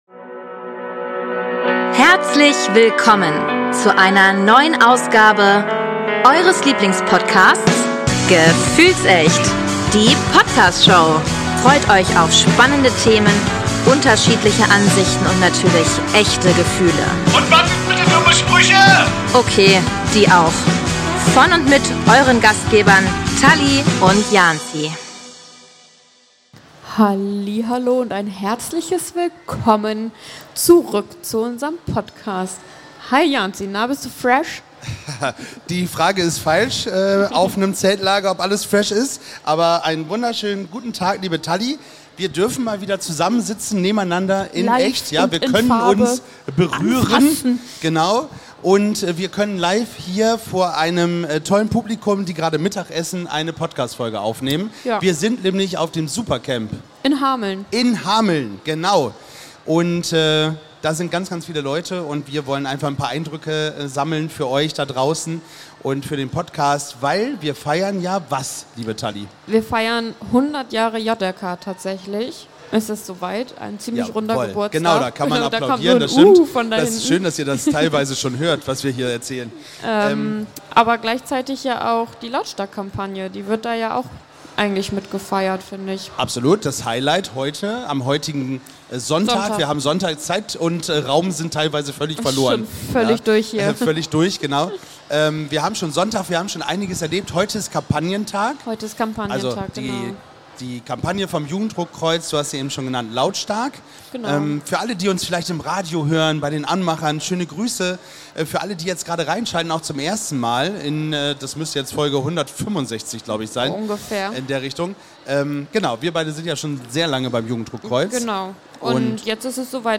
100 Jahre Jugendrotkreuz - Live vom Supercamp 2025 ~ Gefühlsecht - Die Podcast Show Podcast